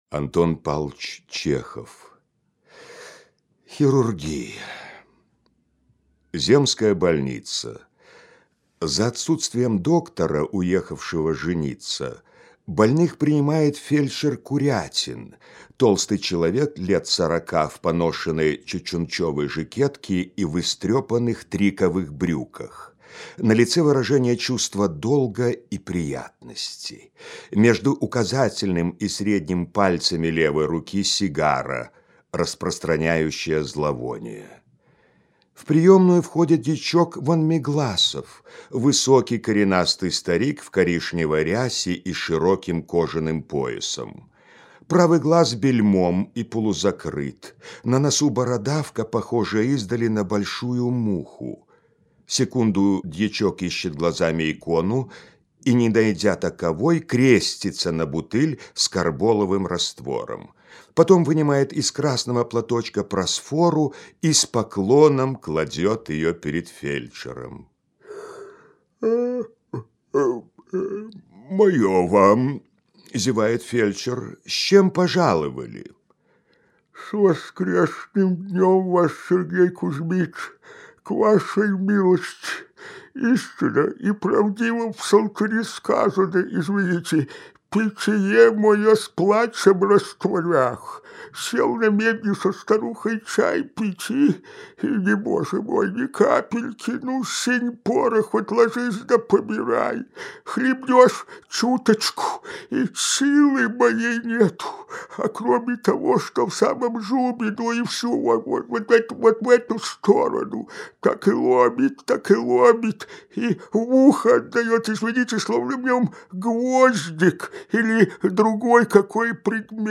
Хирургия – Чехов А.П. (аудиоверсия)
Спасибо отличное качество! 🤗